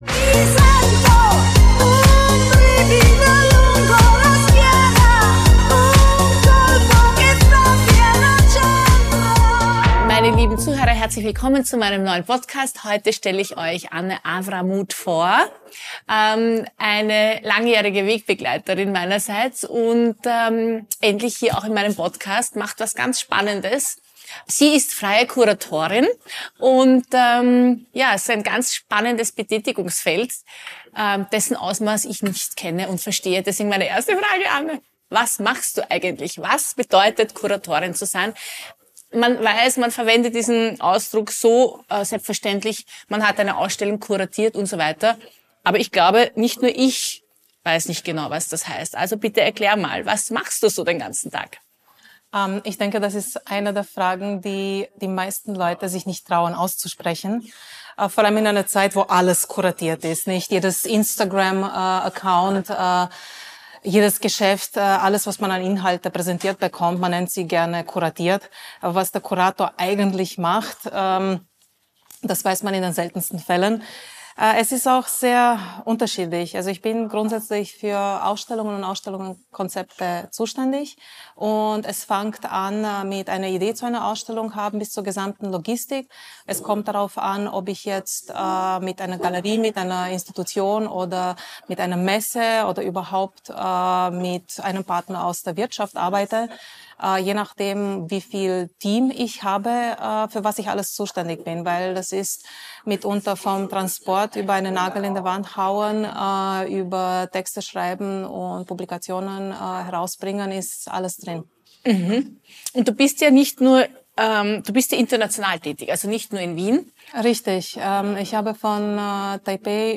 Zwischen Drink und Dialog entstehen Gespräche, die Tiefe haben - mal leise, mal laut, aber es immer wird Tacheles geredet.